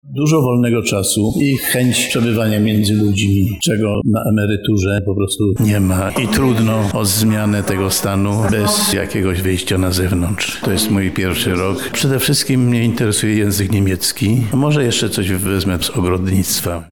Uroczystość odbyła się dziś (03.10) w Centrum Kongresowym Uniwersytetu Przyrodniczego.
senior student– mówi rozpoczynający studia senior